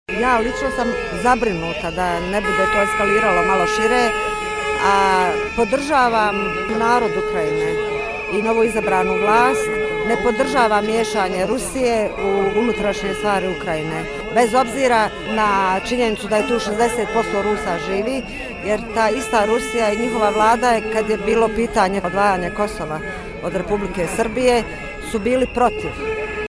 Pitali smo građane na ulicama gradova Crne Gore, Srbije i Bosne i Hercegovine šta misle o krizi u Ukrajini.